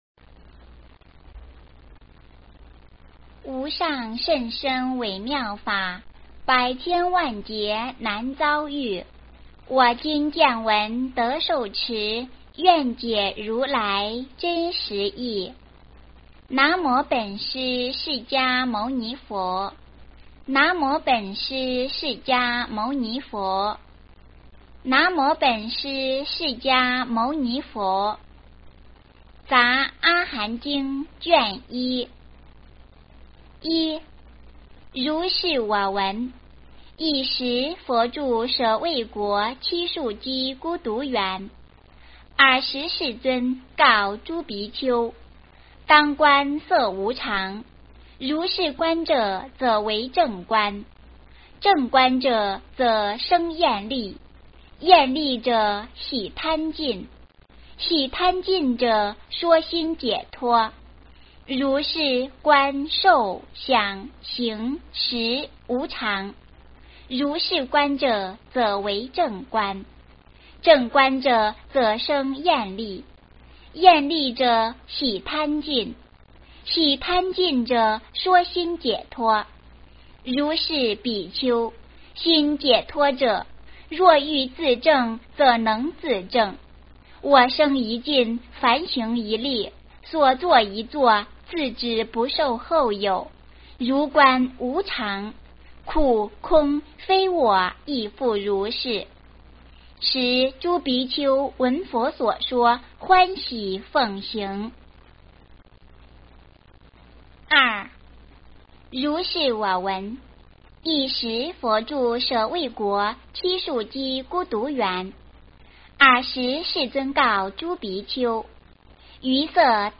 杂阿含经卷一 - 诵经 - 云佛论坛